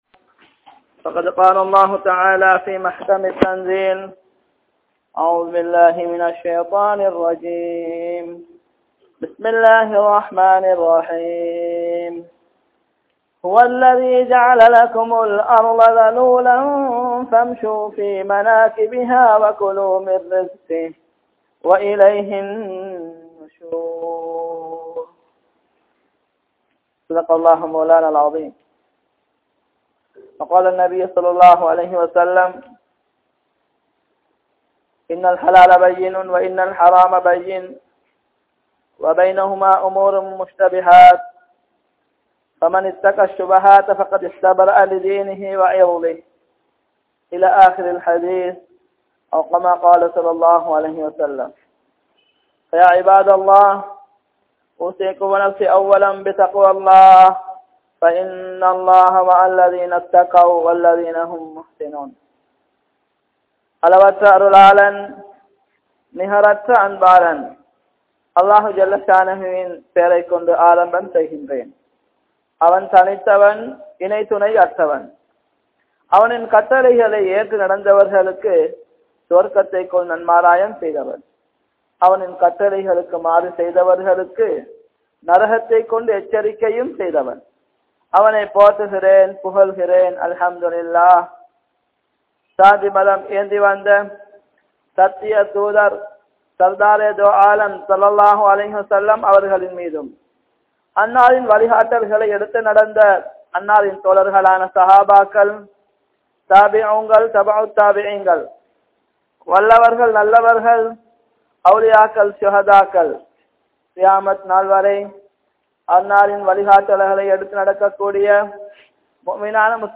Islam Thadai Seitha Viyaafaaram (இஸ்லாம் தடை செய்த வியாபாரம்) | Audio Bayans | All Ceylon Muslim Youth Community | Addalaichenai
Karawira Jumua Masjidh